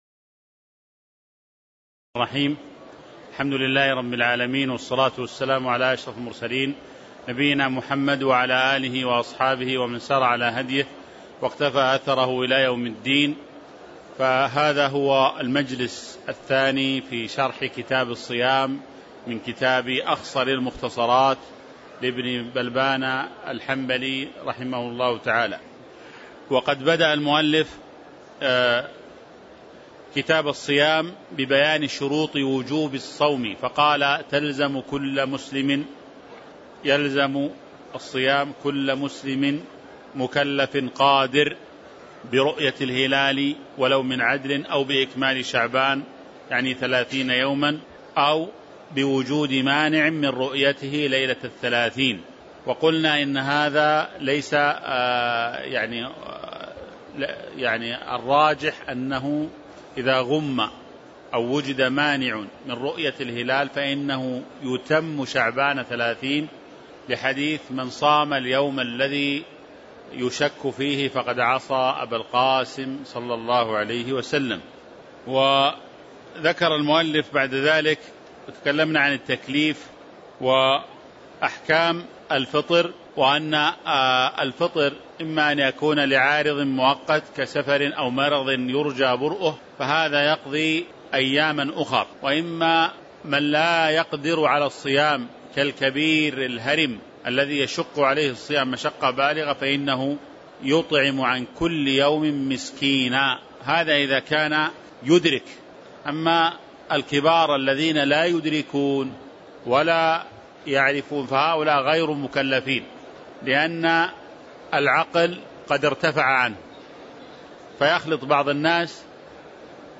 تاريخ النشر ١٤ شعبان ١٤٤٤ هـ المكان: المسجد النبوي الشيخ